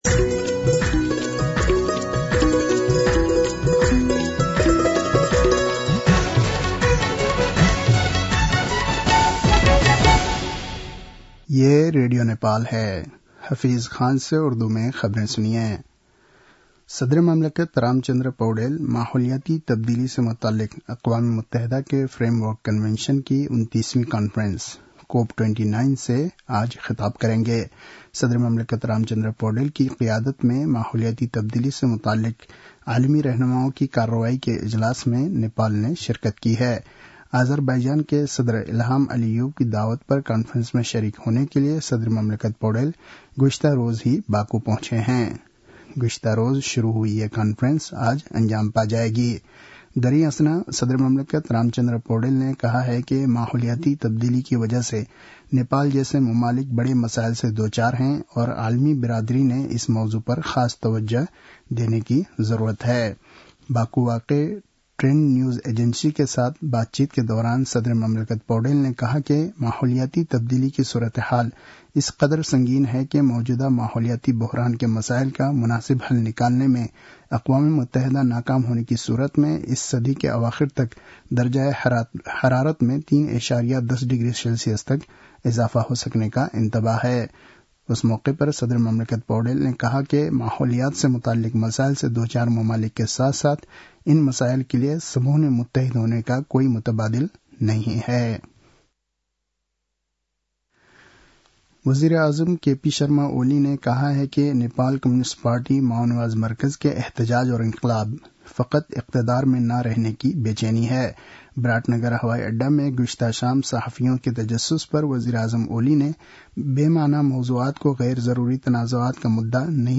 उर्दु भाषामा समाचार : २८ कार्तिक , २०८१